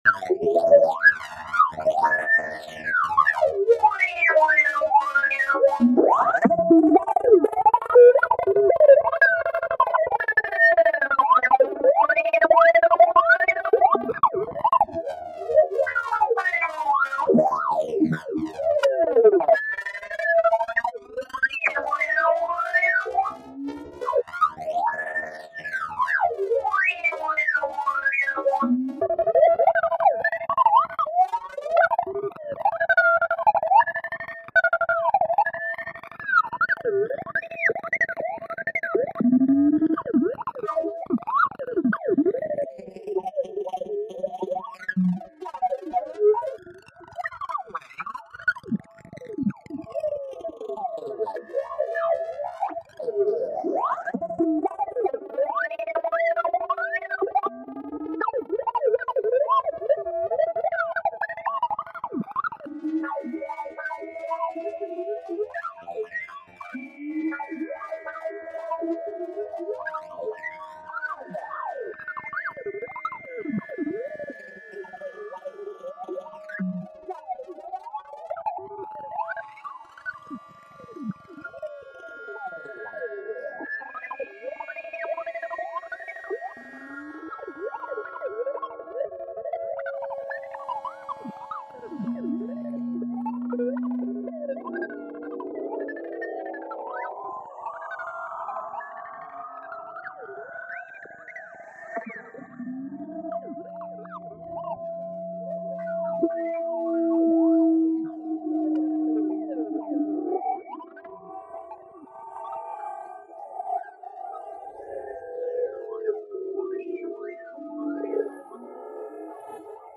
It has samples from the first period mr. trump was in charge over the u.s. of a, but not absolutely so, yet .. anyway .. samples .. amongst which some nonsense uttered by his surroundings, a military band playing for eternal glory. And then some fresh beeps that i generated from a single sine tone { wavipulations of the sine , so to say}.